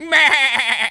goat_baa_stressed_hurt_03.wav